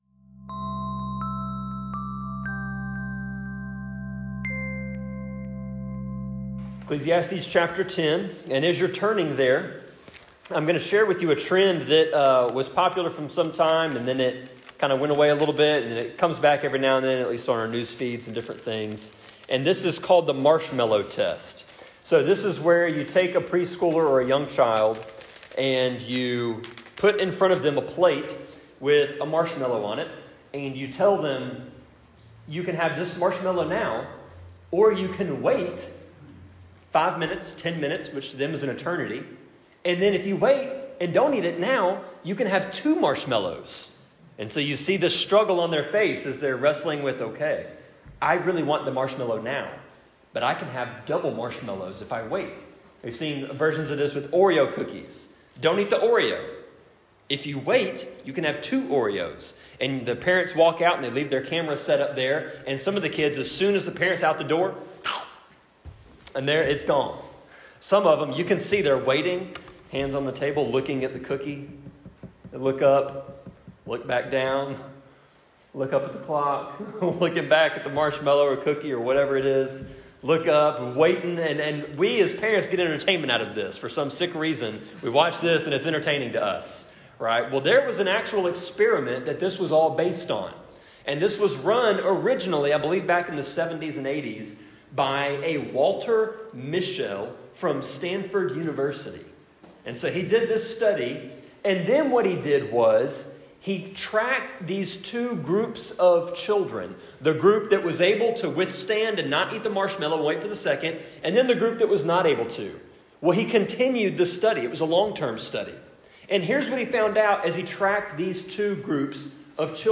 Sermon-25.5.4-CD.mp3